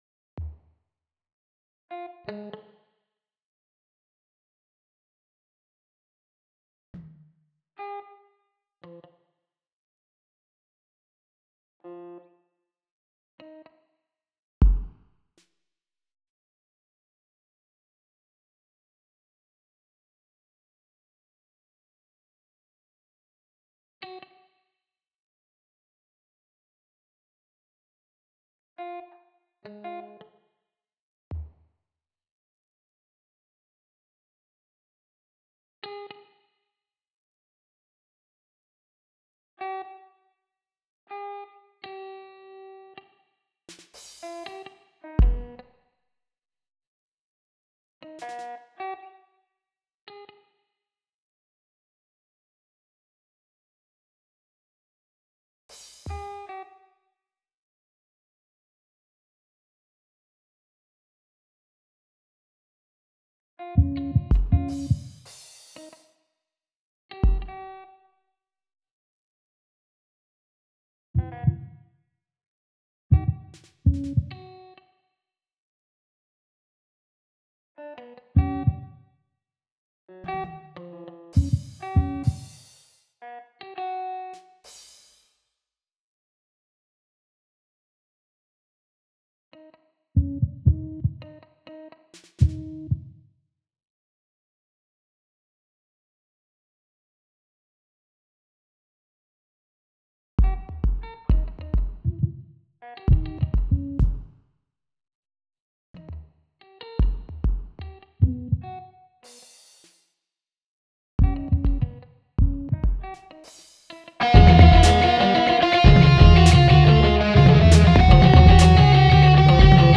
This sound file was generated via a MIDI file exported from the Dorico notation software. Such things are always somewhat horrible: Not only do the sampled or synthesised sounds fall way short of the richness of acoustic instruments played by humans, but the phrasing, timing, and dynamic shaping that human musicians bring to interpretation are all pretty much non-existent in the MIDI version.
To create the demo, I used two different Native Instruments electric guitar sampling instruments for the solo parts, plus a different one for the bass. The percussion part was rendered using a custom Native Instruments Battery drum kit. All the ripieno parts were synthesised with an electric piano physical model from pianoteq.
open-control1-dorico-midi-prod.m4a